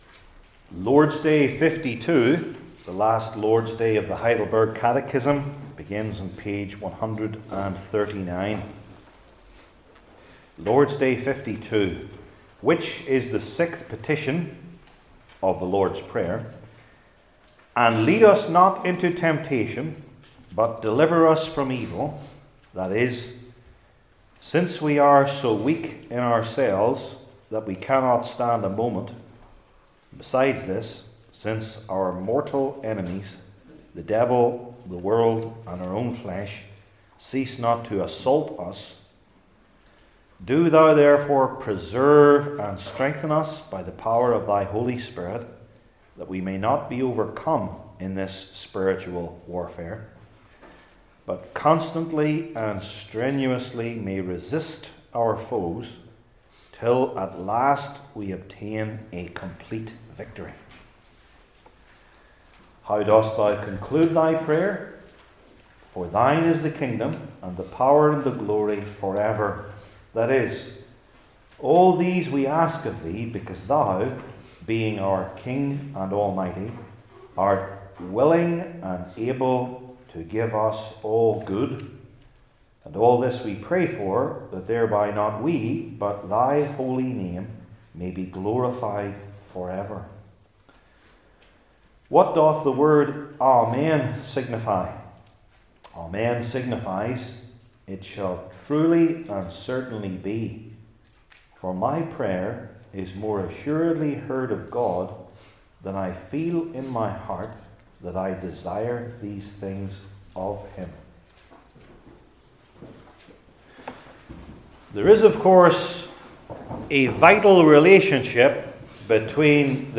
Heidelberg Catechism Sermons I. The Source of Temptation II.